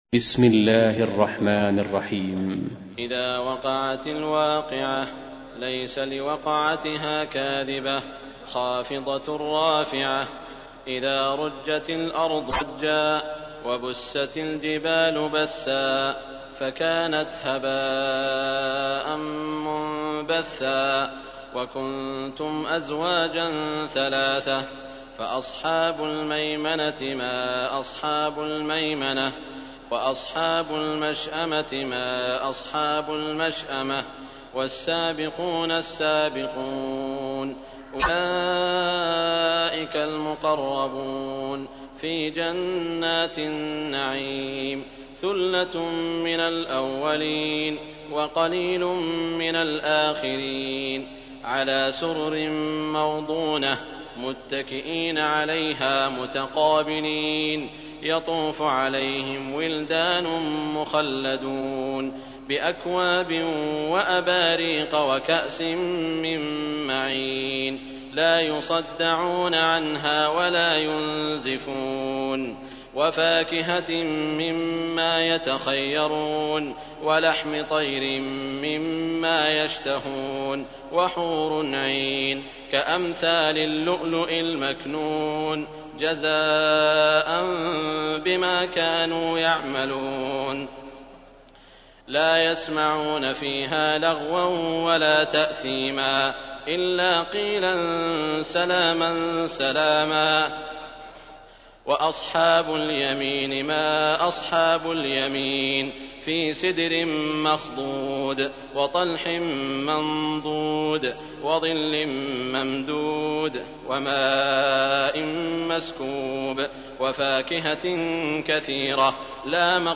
تحميل : 56. سورة الواقعة / القارئ سعود الشريم / القرآن الكريم / موقع يا حسين